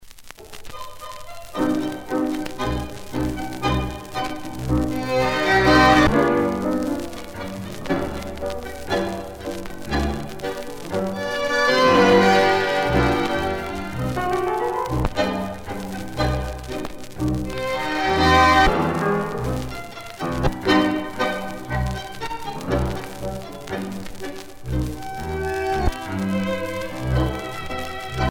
danse : tango